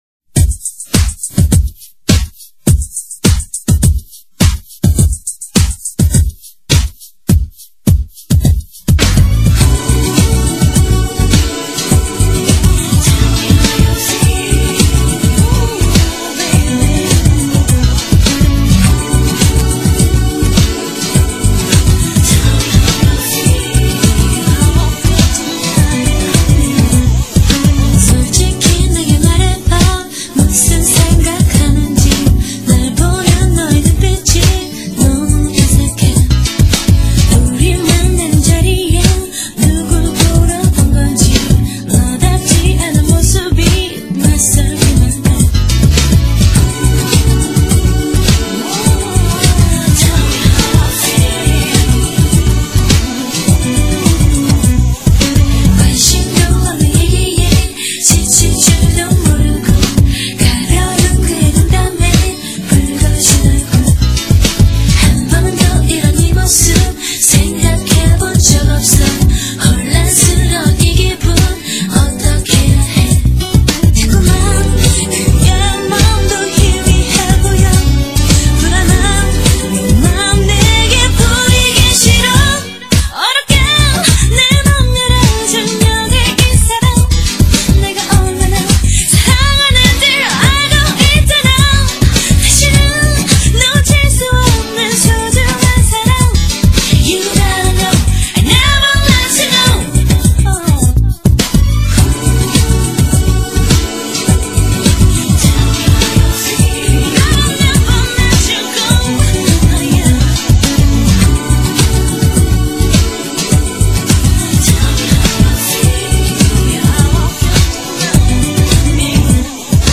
BPM103--1
Audio QualityPerfect (High Quality)